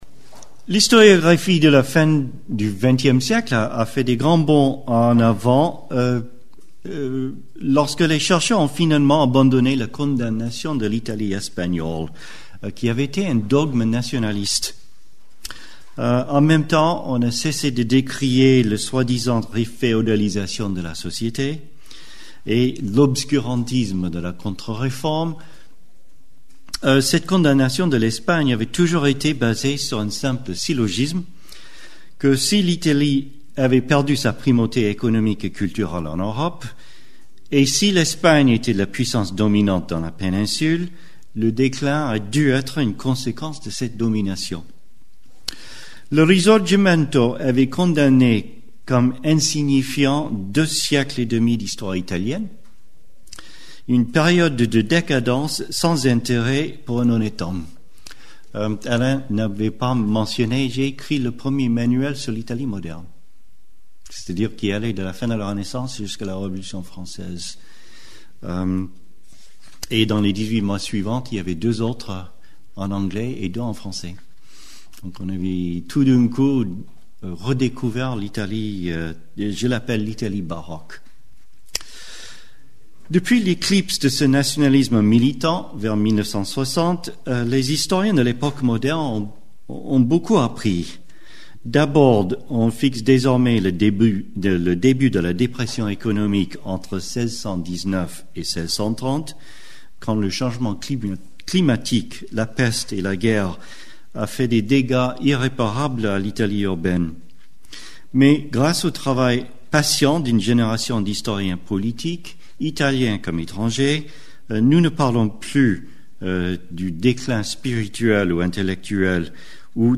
A travers l’étude du positionnement des « petits états italiens », c'est-à-dire les duchés de Parme, Mantoue, Modène, de la république de Gênes, et dans une moindre mesure de Savoie et de Toscane, la conférence vise à analyser les rapports de force en Italie du Nord durant la première partie de la guerre de Trente Ans (1618-1648). Or, cette guerre n’a pas concerné que le monde germanique et flamand, mais elle a aussi touché la péninsule italienne.